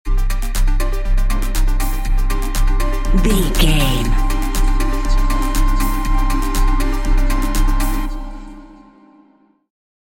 Modern Electronic Stinger.
Epic / Action
Fast paced
Aeolian/Minor
Fast
dark
futuristic
groovy
aggressive
synthesiser
drum machine
house
electro dance
synth leads
synth bass
upbeat